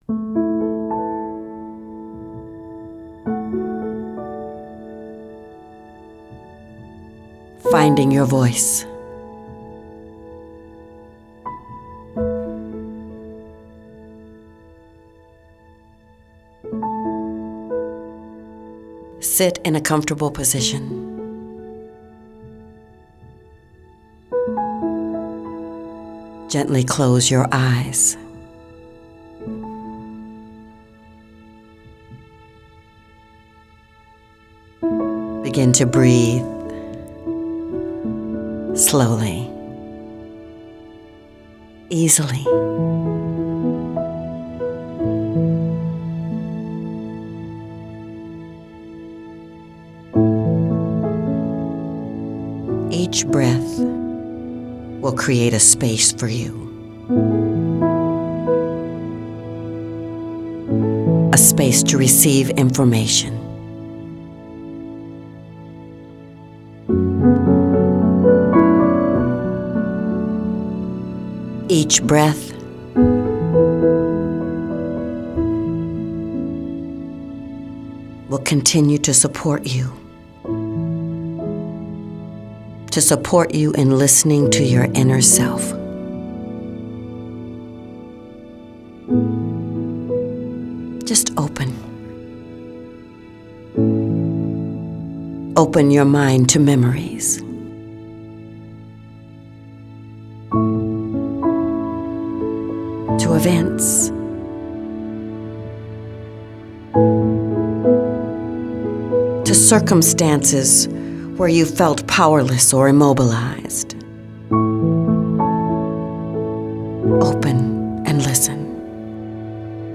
Finding Your Voice Meditation